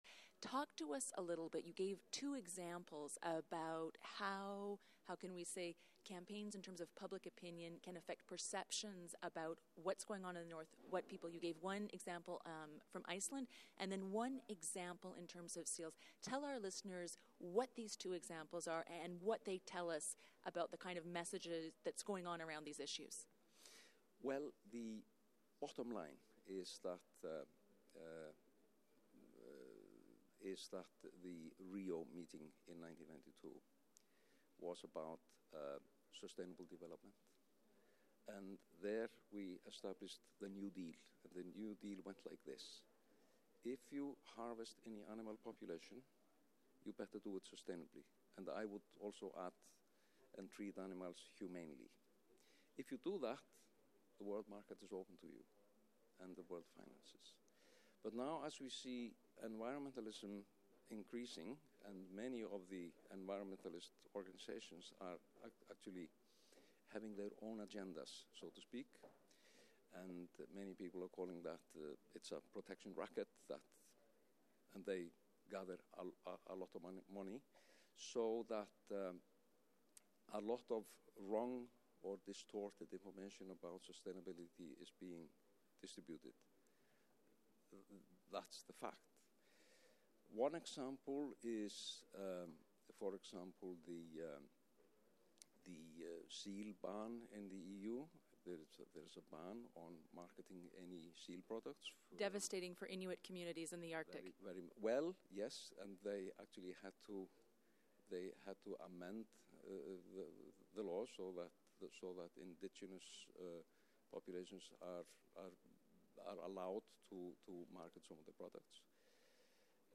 Feature Interview: Hunting culture under stress in Arctic